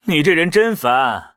文件 文件历史 文件用途 全域文件用途 Kagon_tk_03.ogg （Ogg Vorbis声音文件，长度1.4秒，119 kbps，文件大小：20 KB） 源地址:地下城与勇士游戏语音 文件历史 点击某个日期/时间查看对应时刻的文件。 日期/时间 缩略图 大小 用户 备注 当前 2018年5月13日 (日) 02:16 1.4秒 （20 KB） 地下城与勇士  （ 留言 | 贡献 ） 分类:卡坤 分类:地下城与勇士 源地址:地下城与勇士游戏语音 您不可以覆盖此文件。